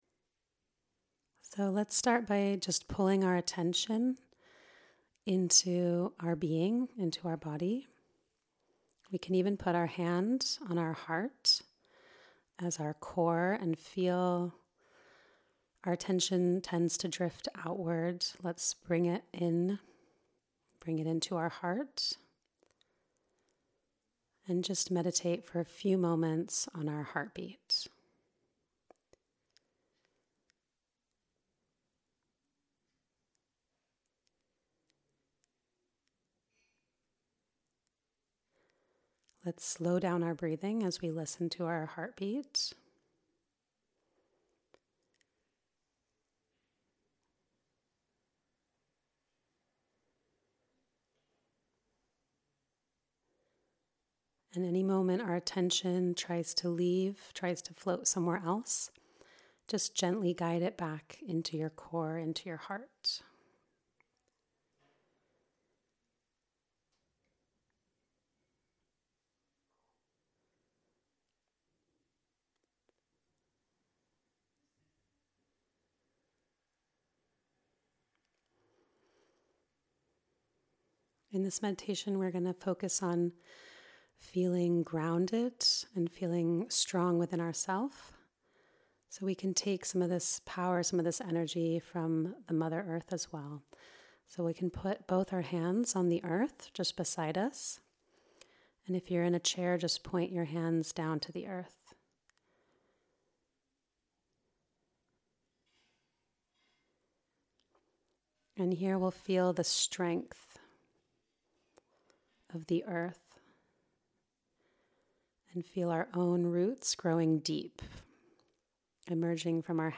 MEDITATION Shri Mataji Nirmala Devi founder 0:00 Delving Deeper All pervading power